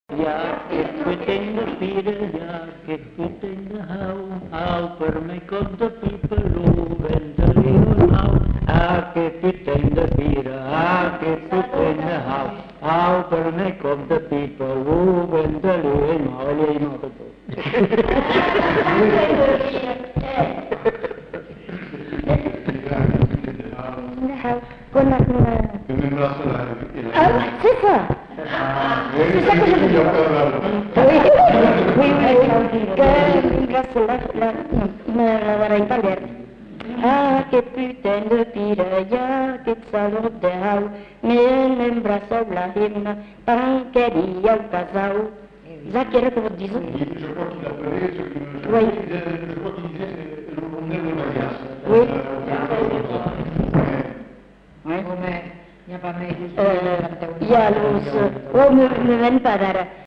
Aire culturelle : Bazadais
Genre : chant
Effectif : 2
Production du son : chanté
Danse : rondeau